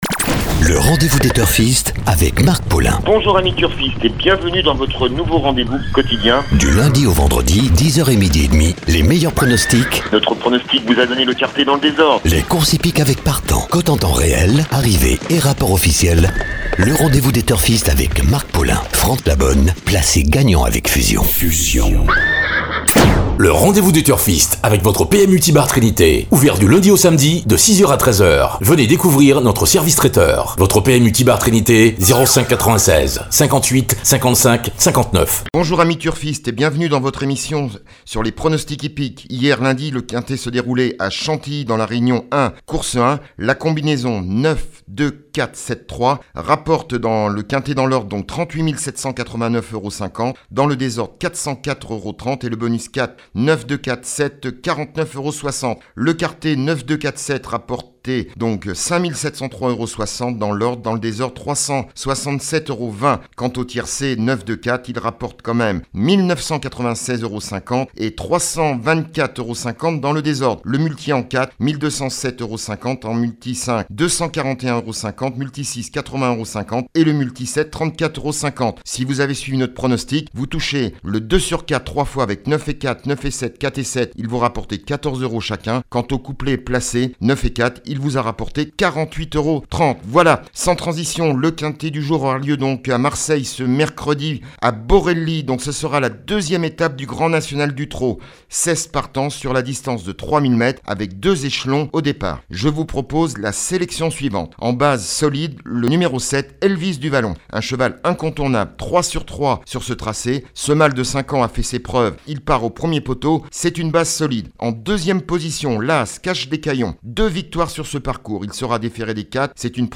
Le journal des sports.